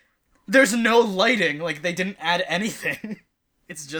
I record a podcast every week through Skype and sometimes my cohosts have their headphone volume set too high, which means we get an echo of everyone else’s recordings onto their tracks.
Track Two is the one with the echo, and Track One is the audio being echoed.